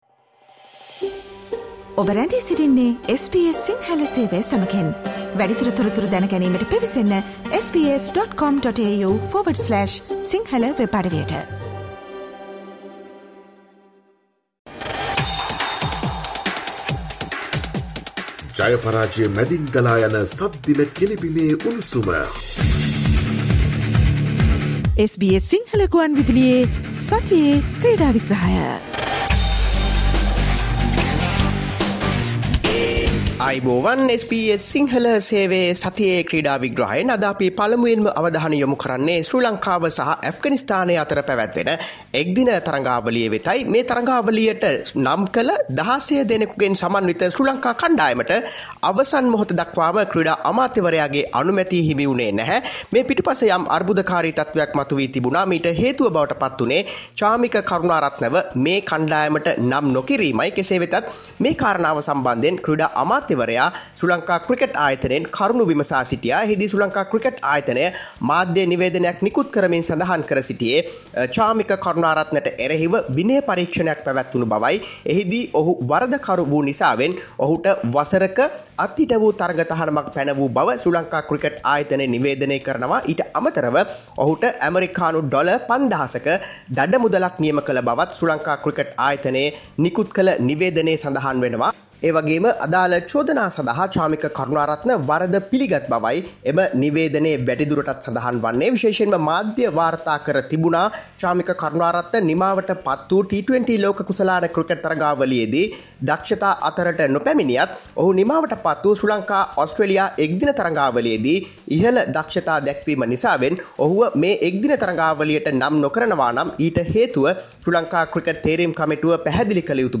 Listen to the SBS Sinhala Radio weekly sports highlights every Friday from 11 am onwards